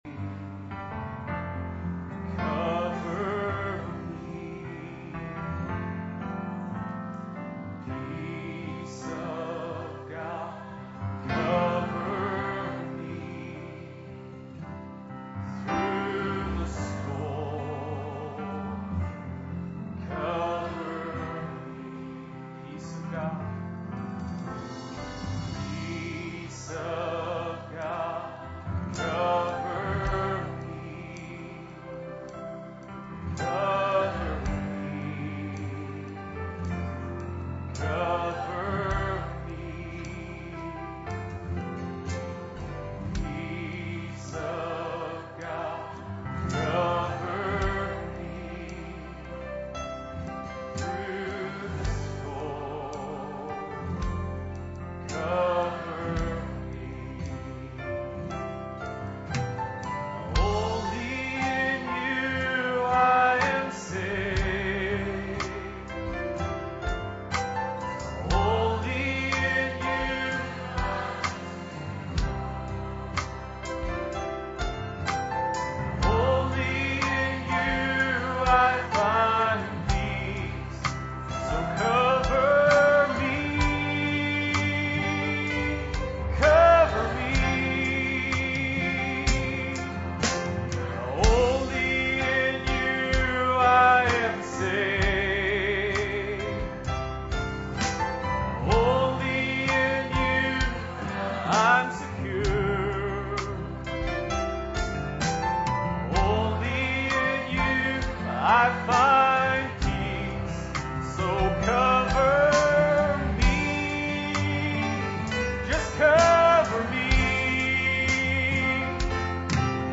For this or any other sermon on DVD, please contact the library using the contact form on the website.